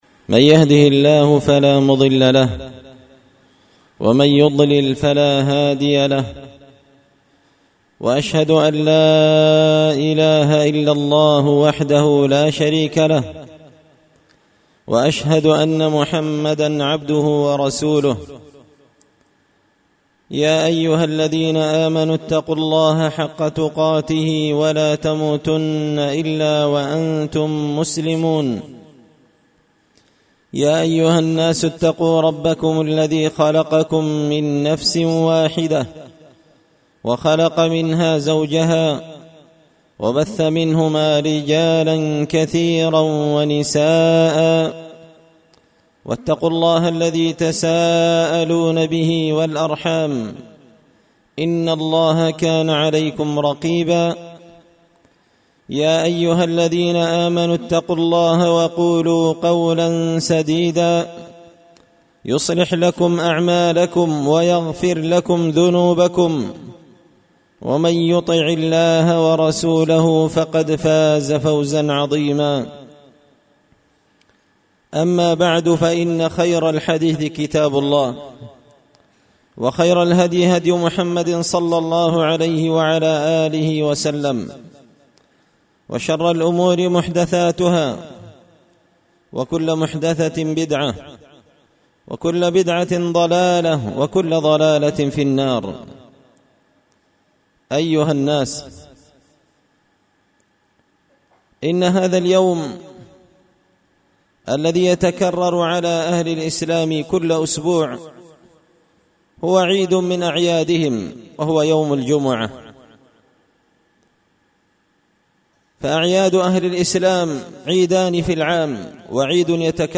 خطبة جمعة بعنوان -آداب الجمعة
دار الحديث بمسجد الفرقان ـ قشن ـ المهرة ـ اليمن